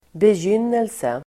Uttal: [bej'yn:else]